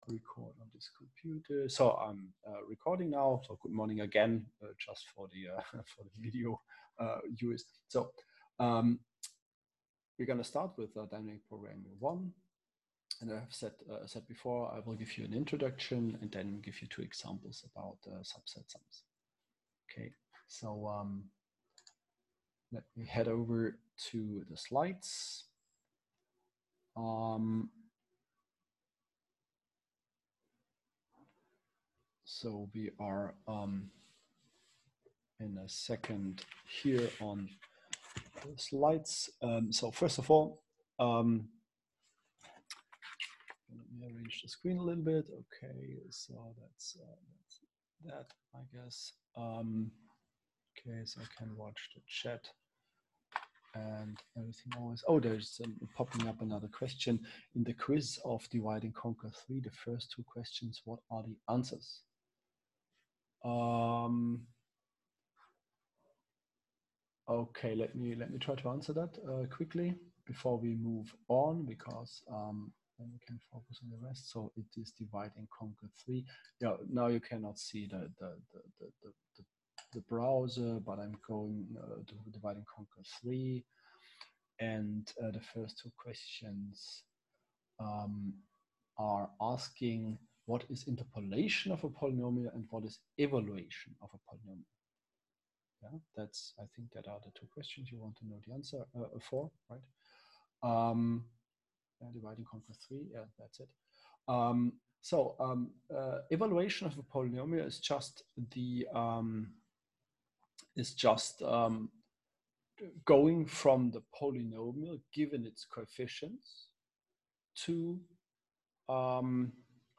Online lecture
13-lecture.m4a